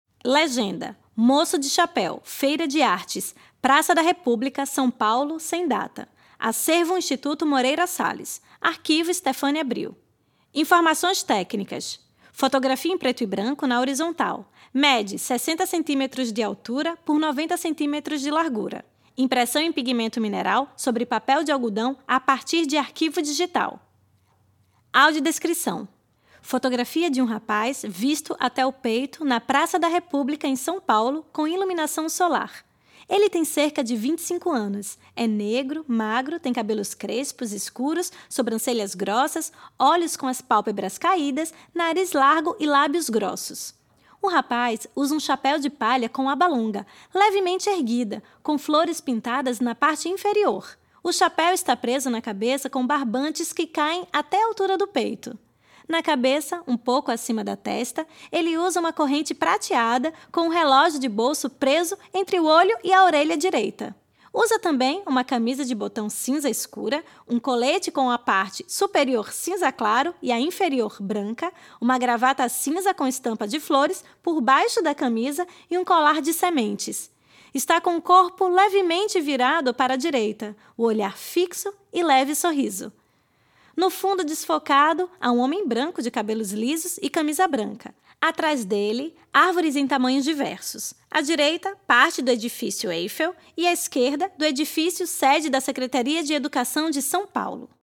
Faixa 1 - Audiodescrição
Faixa-01-AD-foto-Moco-de-chapeu.mp3